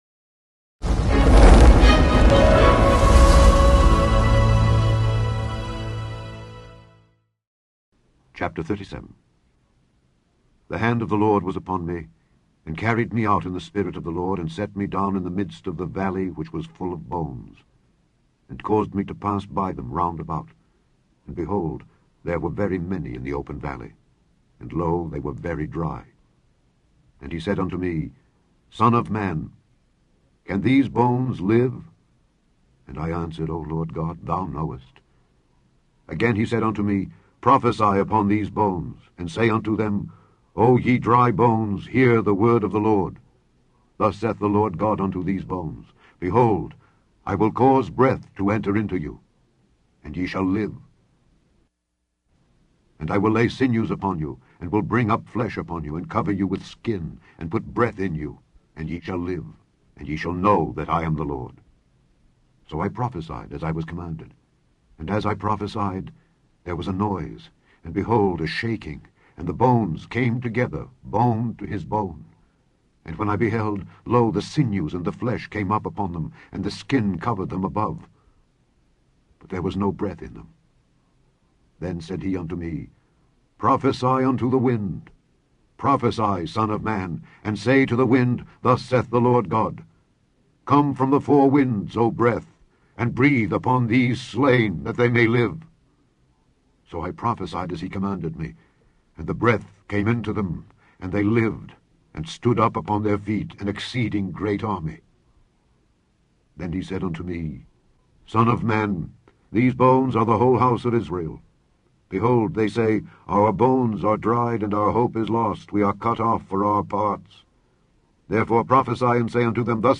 Daily Bible Reading: Ezekiel 37-42
Click on the podcast to hear Alexander Scourby read Ezekiel 37-42.